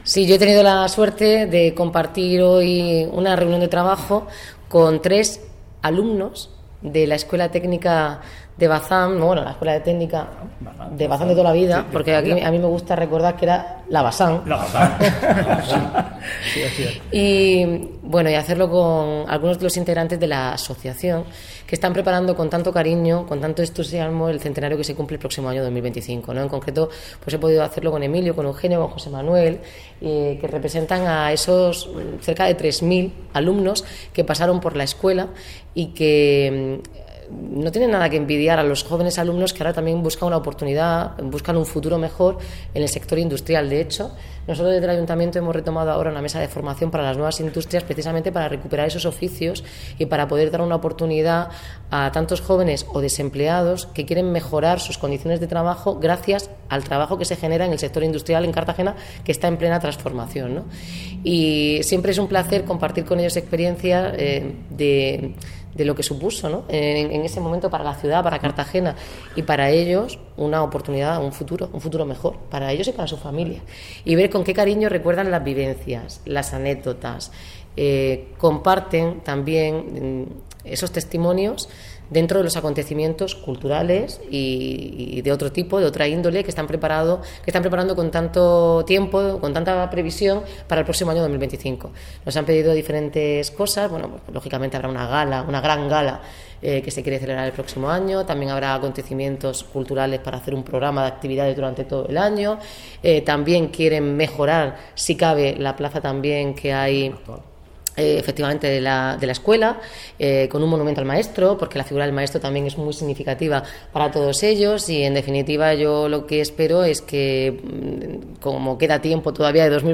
Declaraciones de Noelia Arroyo